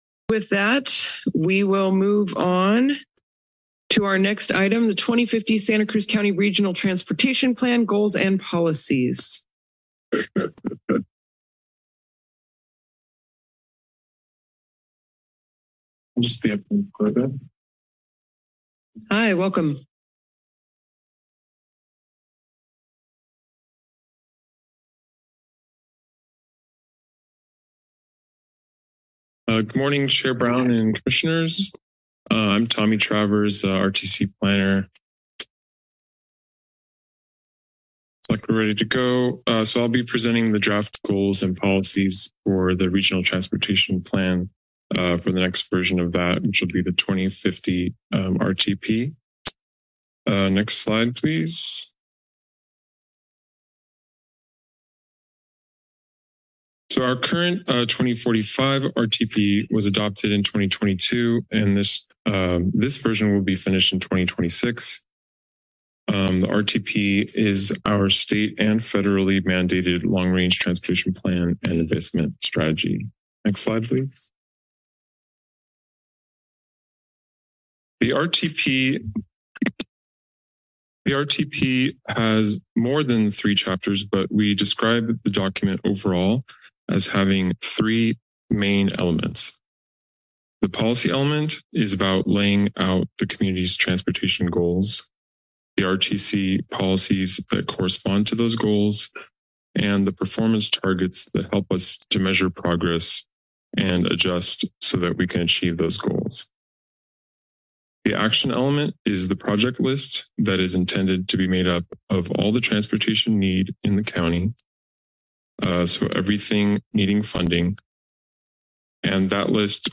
04/04/24 - SCCRTC - Part 11 - 2050 Regional Transportation Plan Presentation